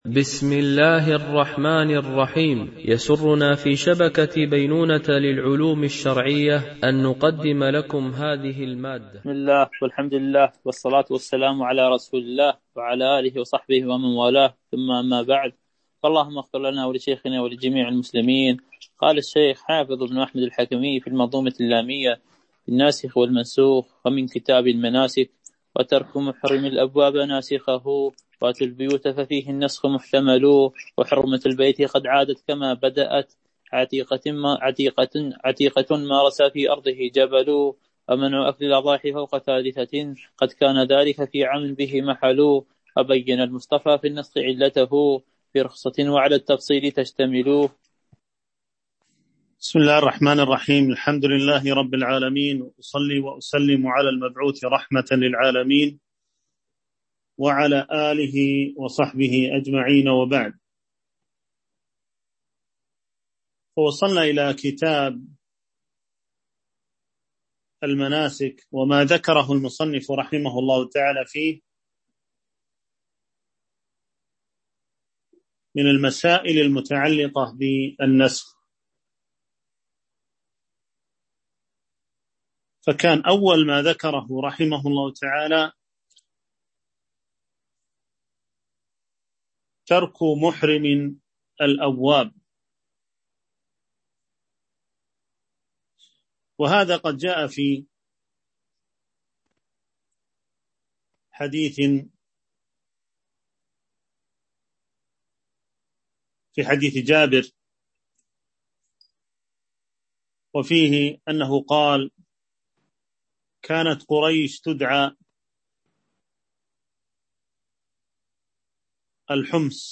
شرح المنظومة اللامية في الناسخ والمنسوخ - الدرس 17